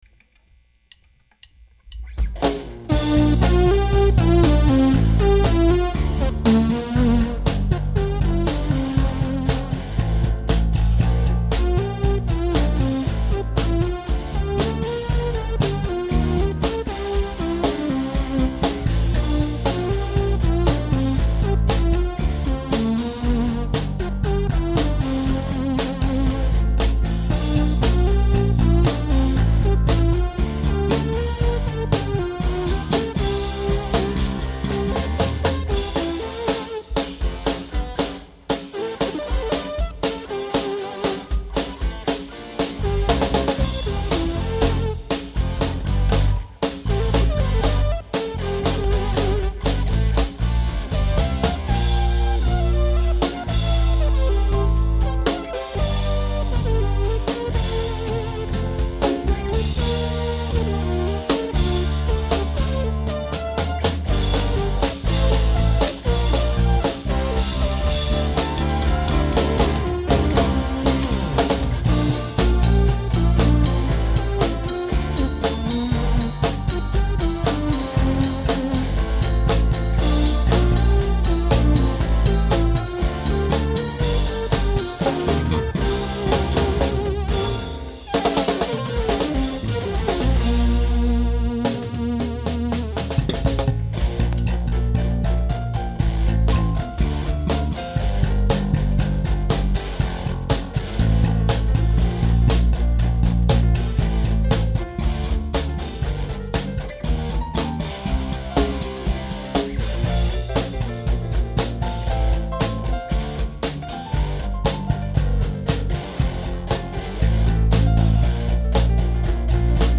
잘하십니다아~~ 이위 소리 좋은데요~ 혹시 모듈 3000m 을 쓰셨나요?
그 디튠 됀 소리... ^^
공연실황 라인아웃
EWI